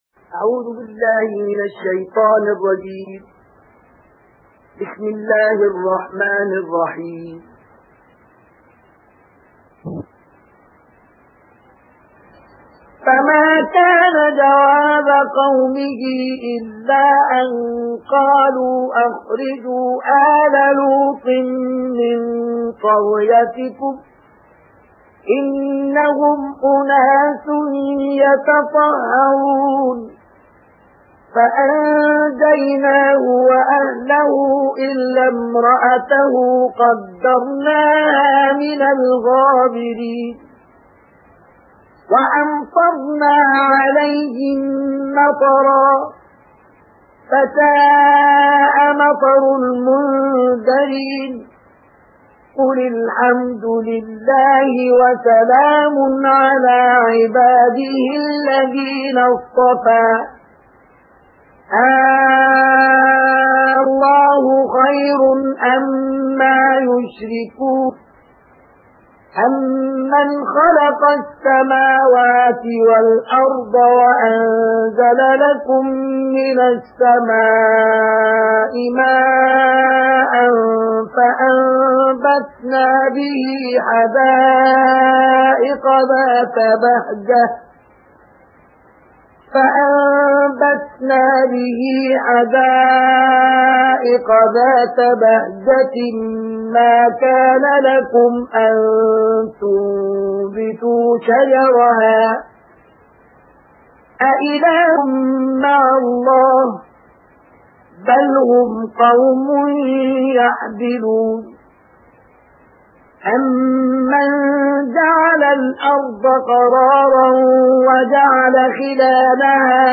صوت/ ترتیل جزء بیستم قرآن توسط "مصطفی‌اسماعیل"